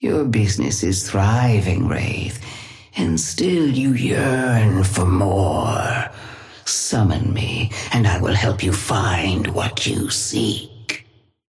Sapphire Flame voice line - Your business is thriving, Wraith.
Patron_female_ally_wraith_start_06.mp3